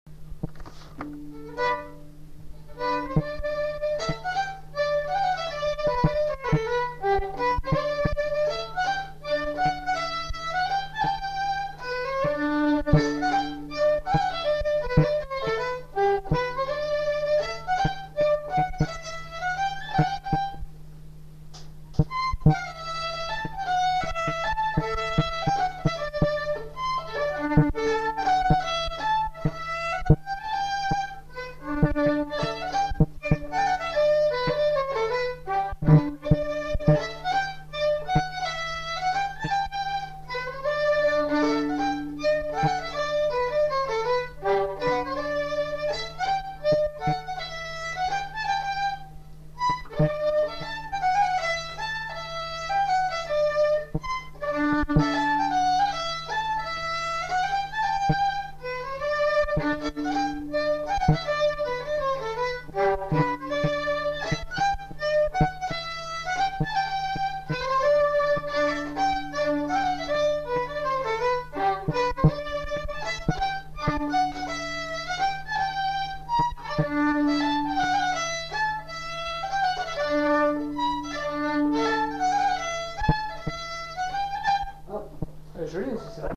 Lieu : Saint-Michel-de-Castelnau
Genre : morceau instrumental
Instrument de musique : violon
Danse : mazurka